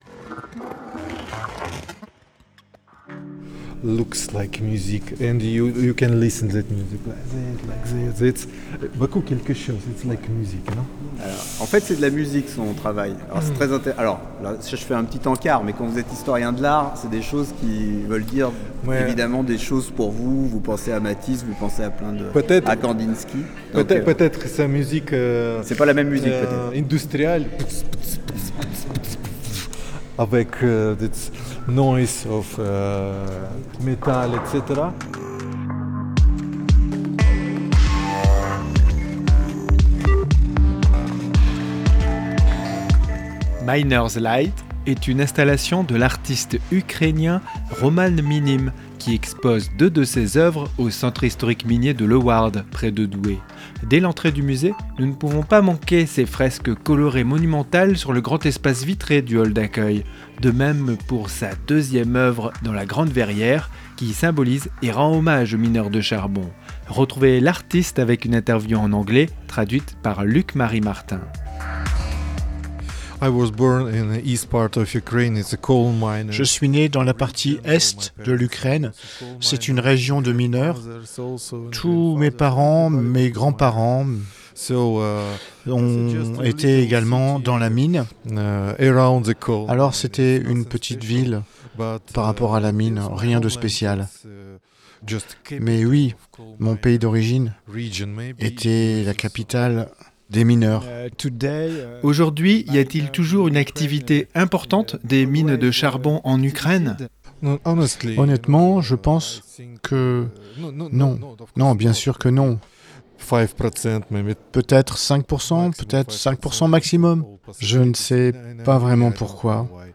REPORTAGE-2510-Un-artiste-Ukrainien-expose-au-Centre-Historique-Minier-de-Lewarde.mp3